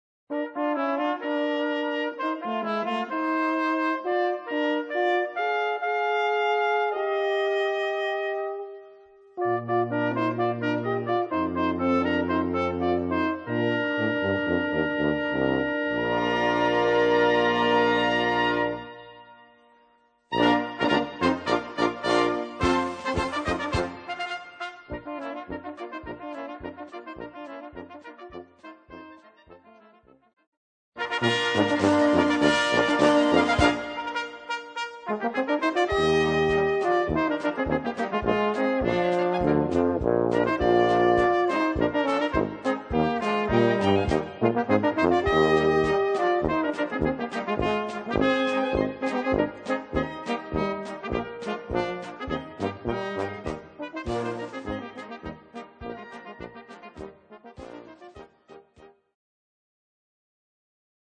Solo für Flügelhorn und Tenorhorn
Blasorchester